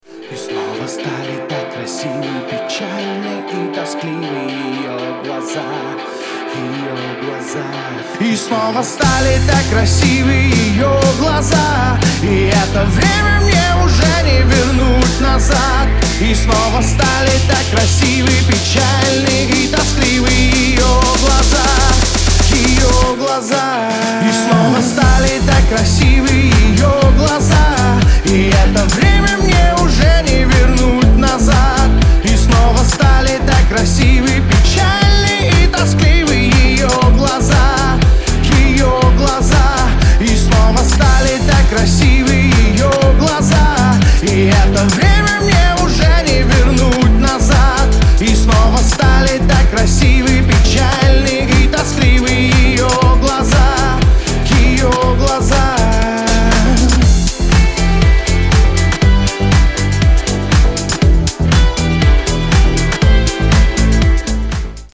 • Качество: 256, Stereo
dance
club